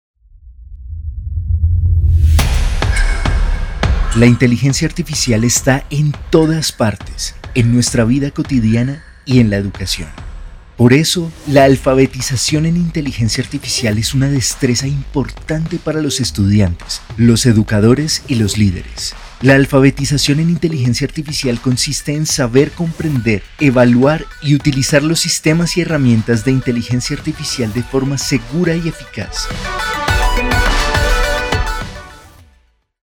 Ma voix est expressive et polyvalente.
Articuler
Assuré
Croyable